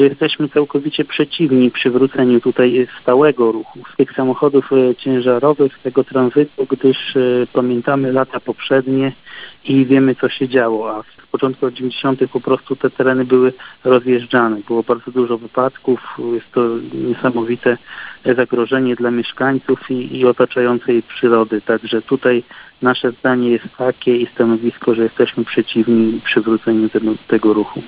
O sprawie poinformował Radio 5 Robert Bagiński, wójt gminy.